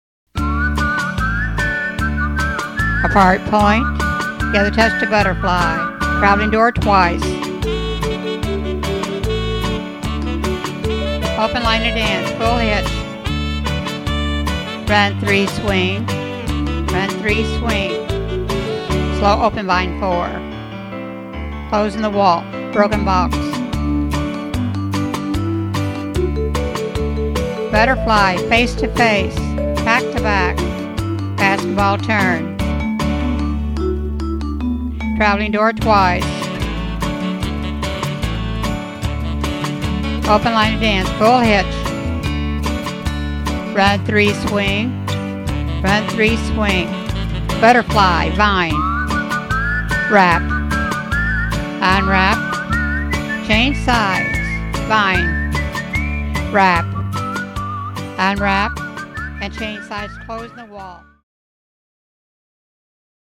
Cued Sample
Two Step, Phase 2